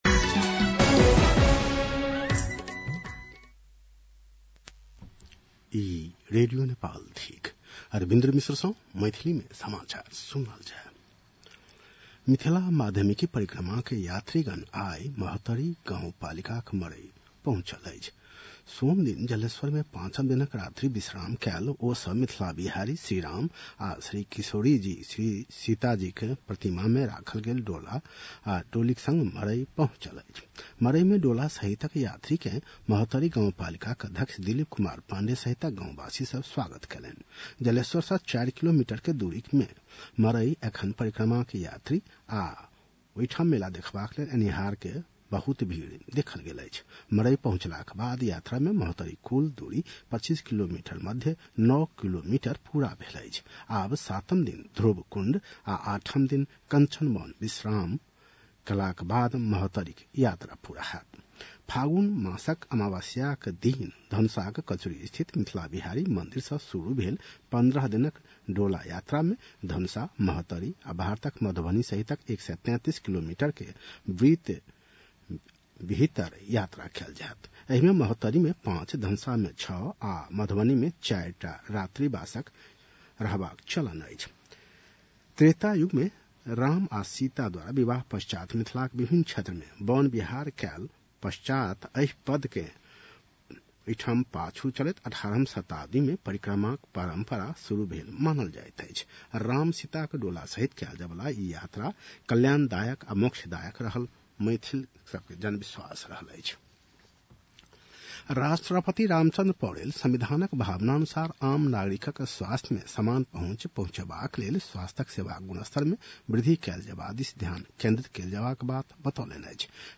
मैथिली भाषामा समाचार : २१ फागुन , २०८१
MAITHALI-NEWS-11-20.mp3